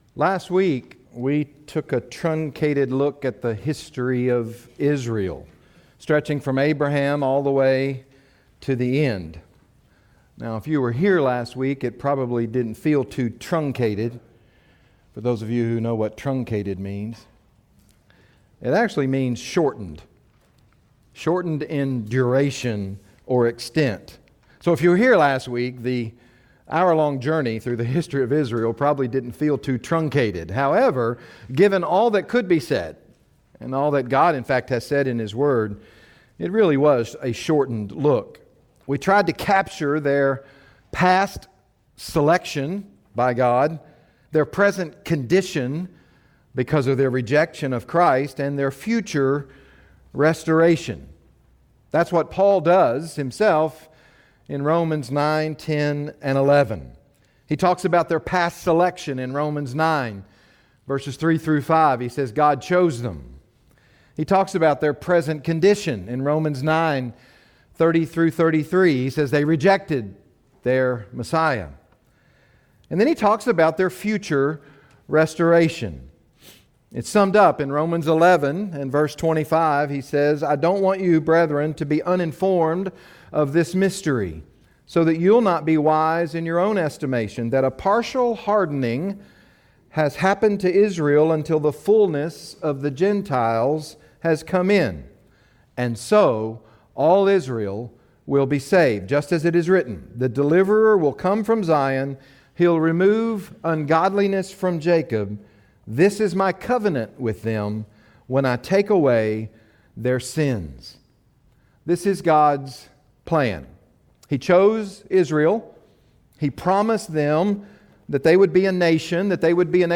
Kept from the Hour of Testing | SermonAudio Broadcaster is Live View the Live Stream Share this sermon Disabled by adblocker Copy URL Copied!